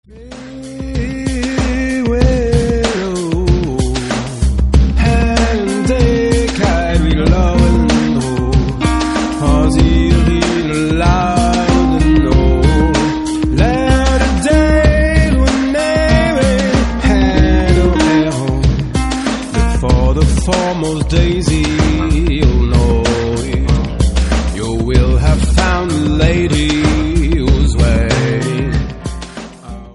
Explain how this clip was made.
MP3 64kbps-Stereo